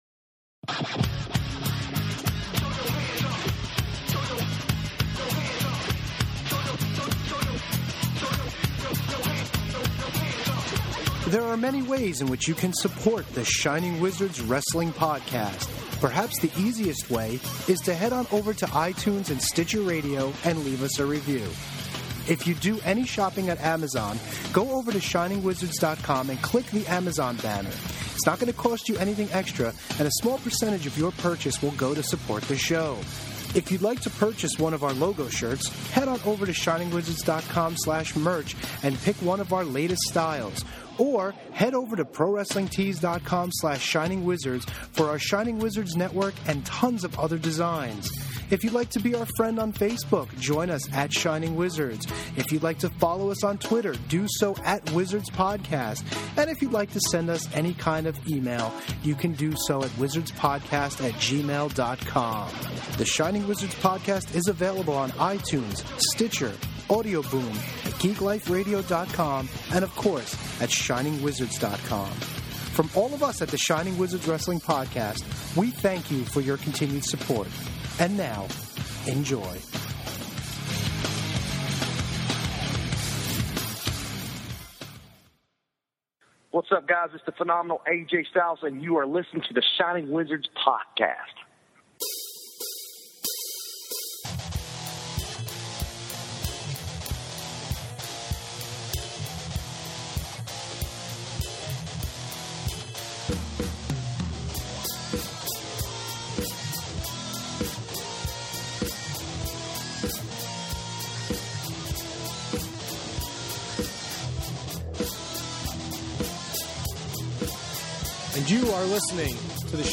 They succeed with Mick Foley on the line, talking about his Wrestlemania Raffle to support the RAINN Foundation.